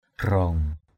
/ɡ͡ɣrɔ:ŋ/ (đg.) rủ nhau, quyến = s’exciter mutuellement. getting excited each other. barak ajak mai, maraong graong mai (tng.) brK ajK =m, m_r” _g” =m phía bắc rủ tới, phía...
graong.mp3